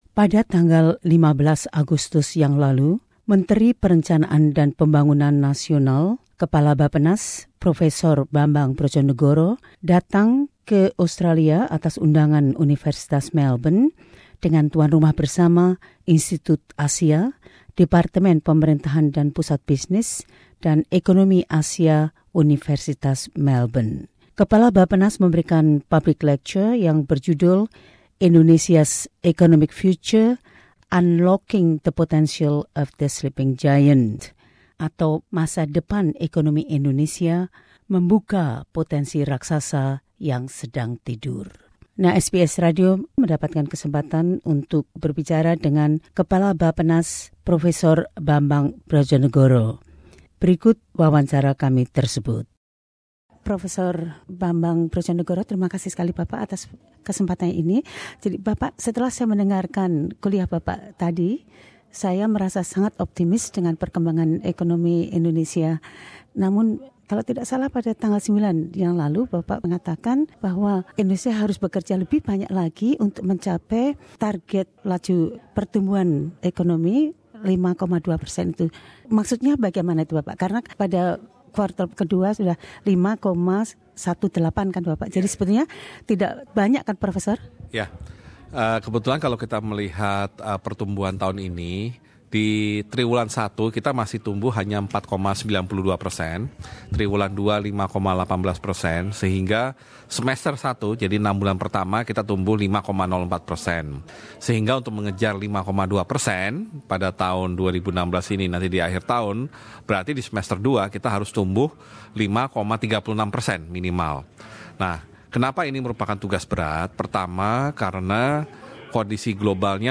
Prof Dr Bambang Permadi Soemantri Brodjonegoro, Menteri Perencanaan dan Pembangunan Nasional/Kepala BAPPENAS saat ini, menjelaskan bagaimana Indonesia dapat memaksimalkan pertumbuhan ekonominya di masa depan.
Prof Dr Bambang Brodjonegoro, MELU – 15 Aug 2016 Source: SBS Indonesian